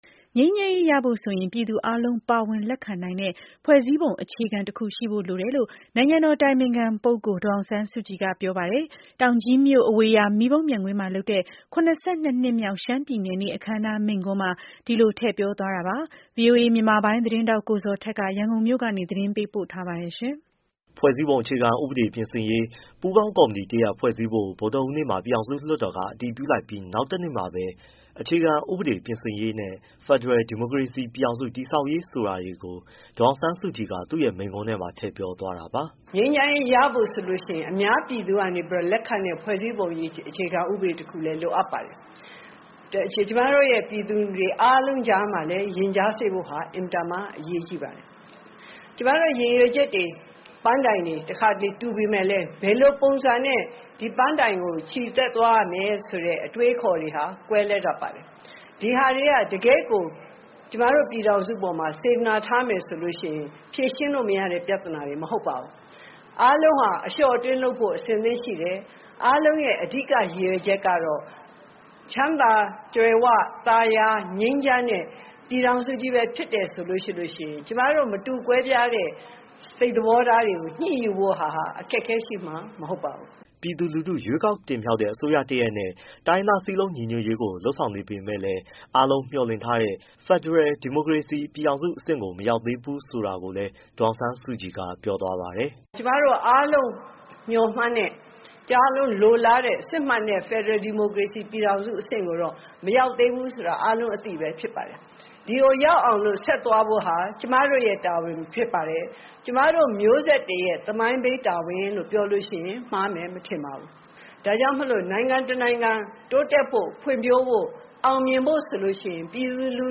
ငြိမ်းချမ်းရေးရဖို့ဆိုရင် ပြည်သူအားလုံး ပါဝင်လက်ခံနိုင်တဲ့ ဖွဲ့စည်းပုံအခြေခံဥပဒေတခု ရှိဖို့လိုတယ်လို့ နိုင်ငံတော် အတိုင်ပင်ခံ ပုဂ္ဂိုလ် ဒေါ်အောင်ဆန်းစုကြည်ကပြောပါတယ်။ တောင်ကြီးမြို့ အဝေရာမီးပုံးပျံကွင်းမှာလုပ်တဲ့ (၇၂) နှစ်မြောက် ရှမ်းပြည်နယ်နေ့ အခမ်းအနားမှာ အဲဒီလို ထည့်ပြောသွားတာပါ။